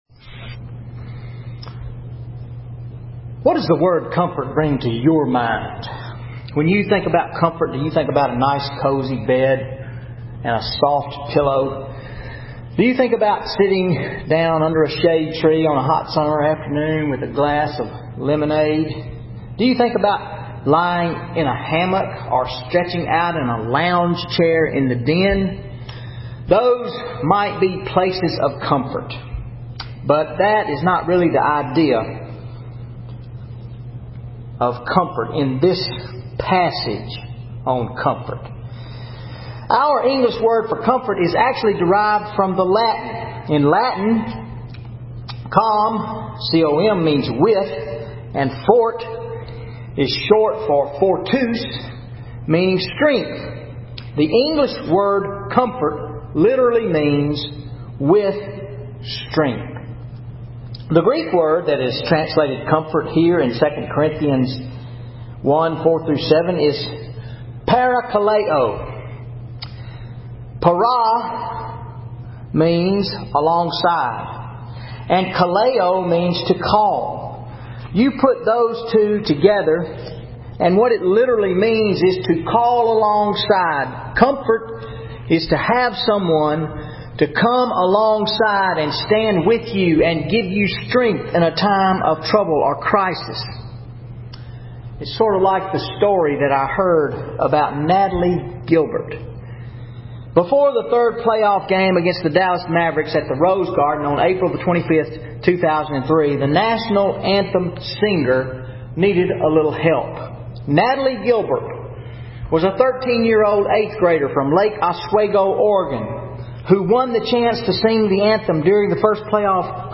May 26, 2013 Sermon II Corinthians 1:1-7
0 Comments VN810145_converted Sermon Audio Previous post Mark 12:38-40 and Matthew 23:1-12 What Kind of Preachers Should I Beware Of?